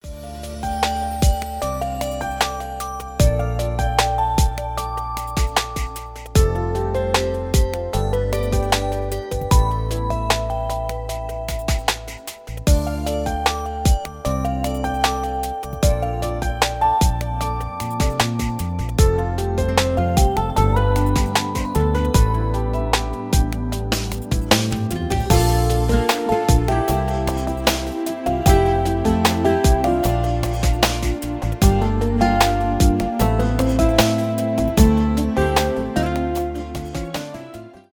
красивая мелодия , инструментальные , без слов , поп
романтические